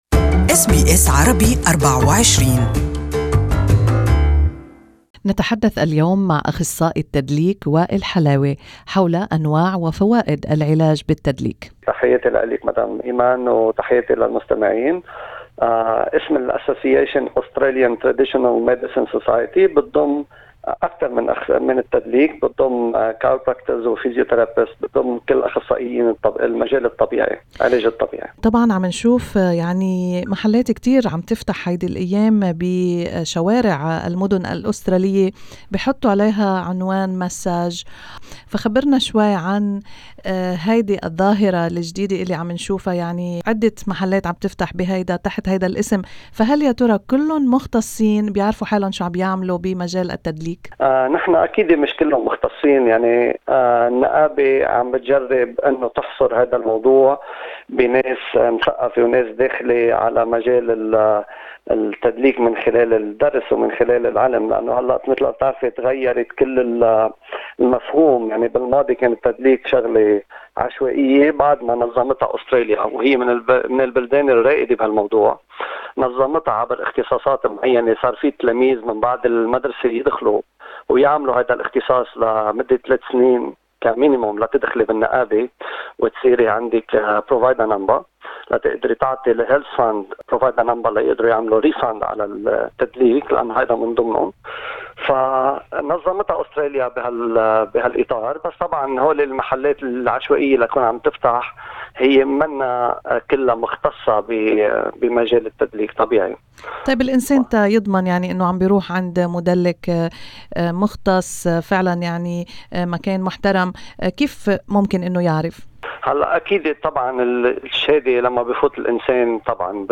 استمعوا إلى اللقاء كاملا تحت الشريط الصوتي.